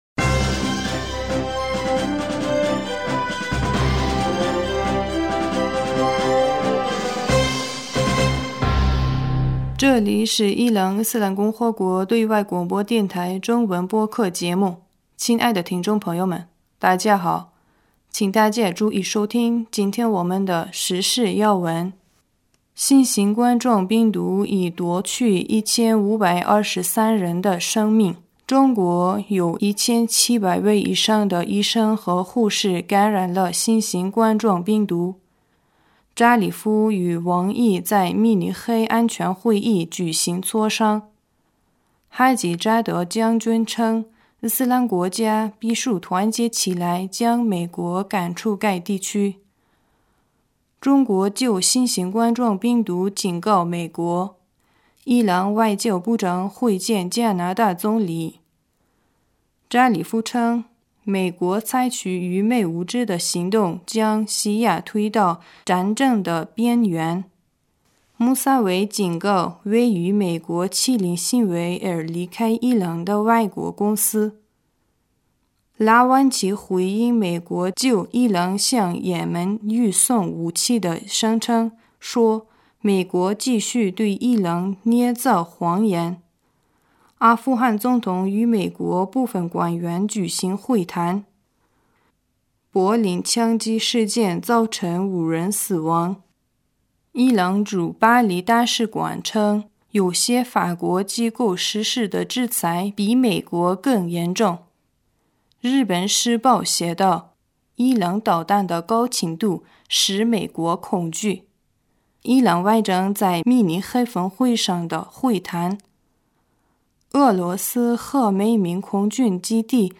2020 年2 月15日 新闻
伊朗华语台消息：2020 年2 月15日 新闻